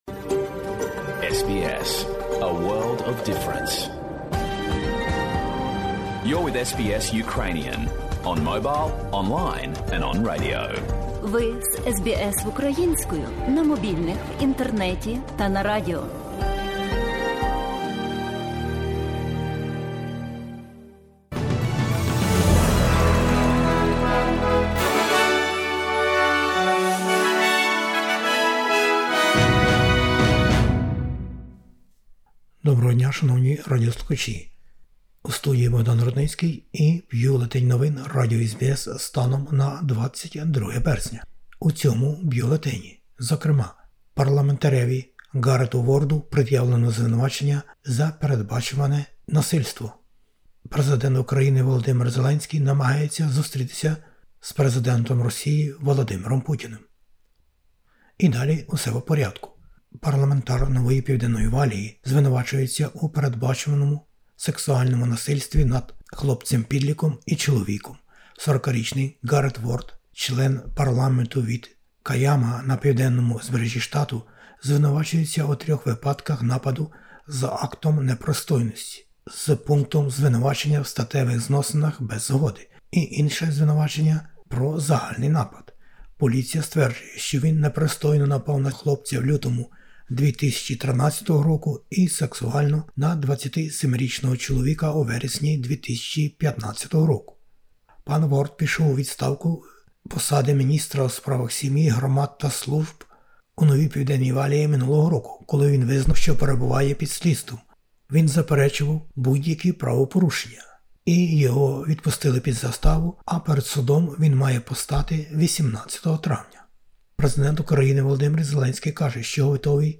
SBS новини про події в Австралії, Україні та світі. Війна в Україні не стихає.